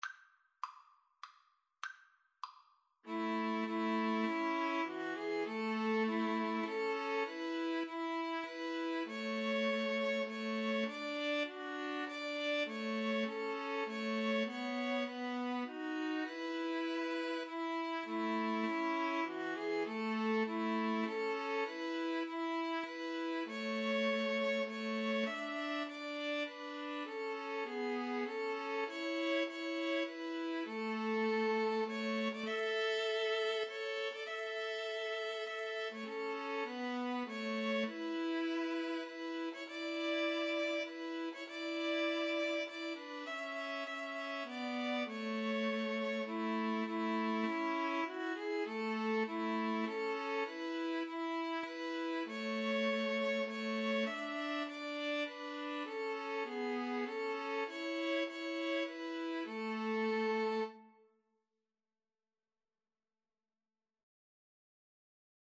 Free Sheet music for 2-violins-viola
3/4 (View more 3/4 Music)
A major (Sounding Pitch) (View more A major Music for 2-violins-viola )
Traditional (View more Traditional 2-violins-viola Music)